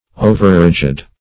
Overrigid \O"ver*rig"id\, a. Too rigid; too severe.
overrigid.mp3